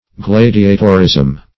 Gladiatorism \Glad"i*a`tor*ism\, n.